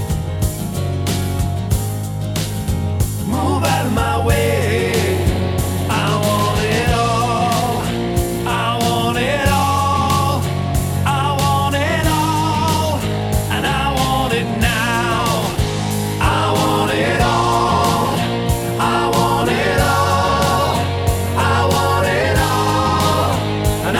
One Semitone Down Rock 3:49 Buy £1.50